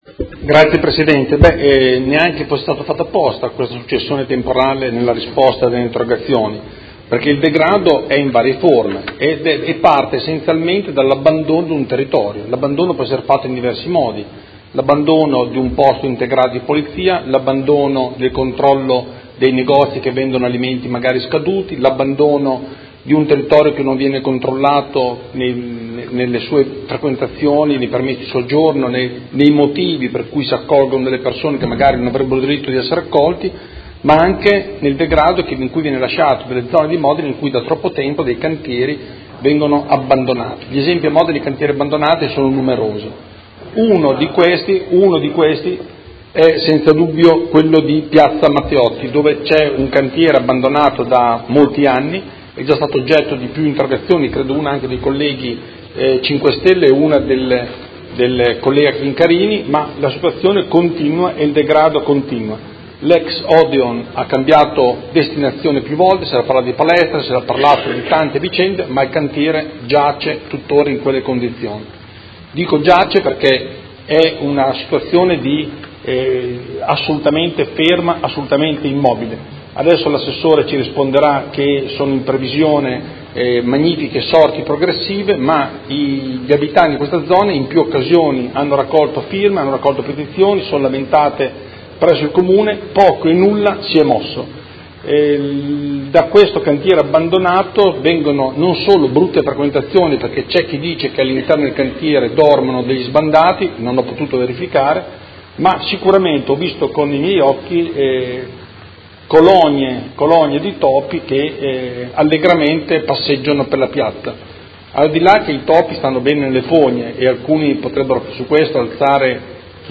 Seduta del 19/10/2017 Interrogazione del Consigliere Galli (FI) avente per oggetto: Invasione di TOPI in Piazza Matteotti; raccolta firme per fermare il degrado.